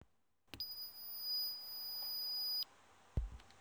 This was recorded with my mobile phone. I can hear the beep at least from 10 meters away.
Actually it could be louder.